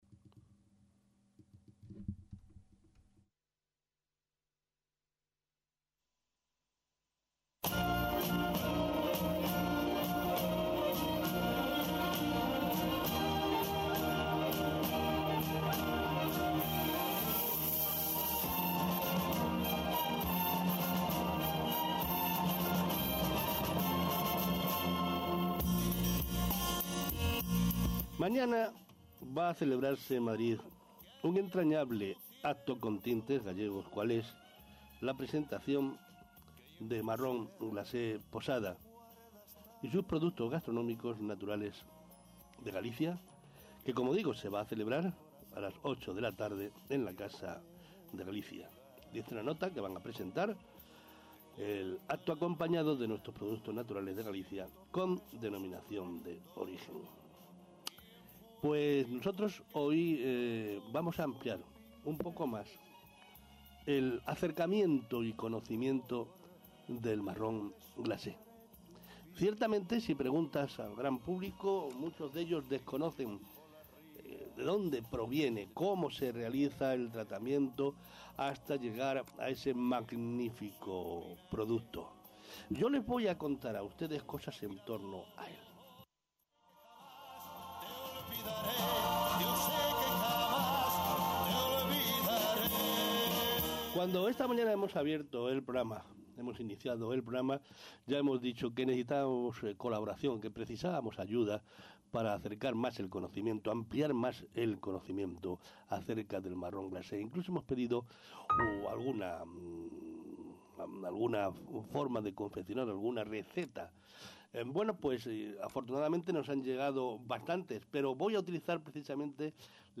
Radio Turismo "España 13 Horas" P ueden descargar la entrevista en formato MP3 para escucharlo en su reproductor de música favorito.